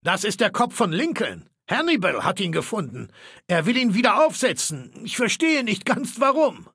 Maleold01_ms06_ms06lincolnshead_00027fa0.ogg (OGG-Mediendatei, Dateigröße: 59 KB.
Fallout 3: Audiodialoge